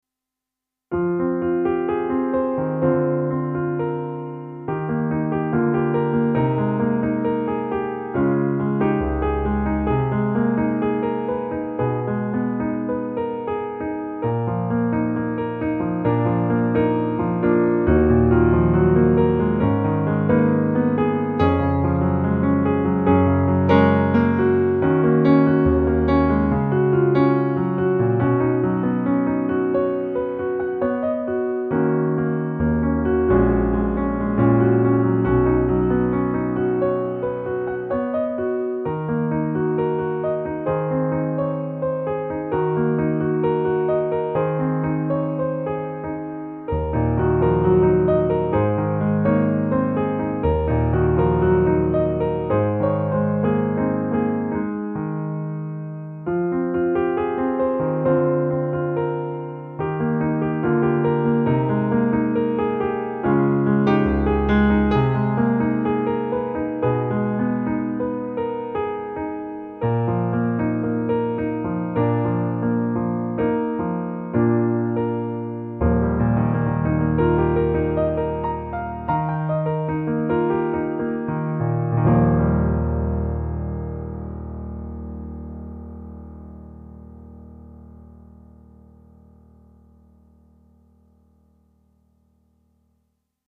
For Yunha Just piano. 2004